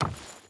Wood Chain Run 5.ogg